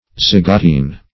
\zy*go*tene"\
zygotene.mp3